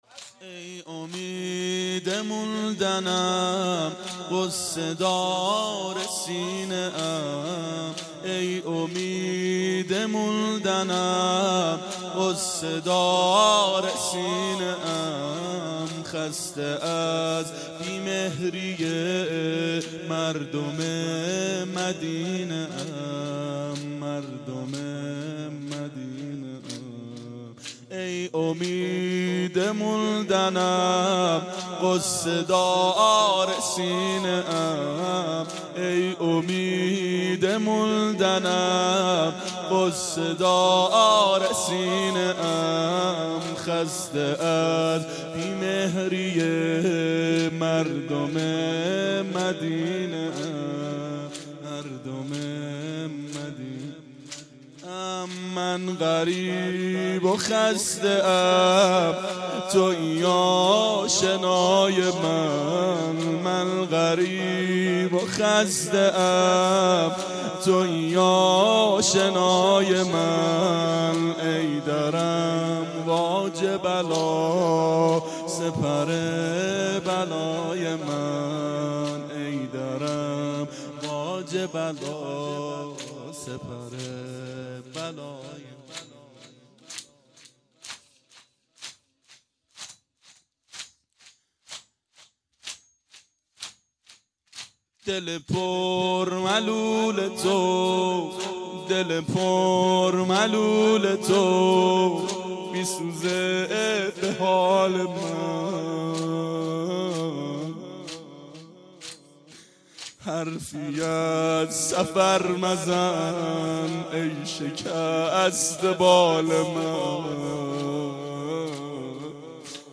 • فاطمیه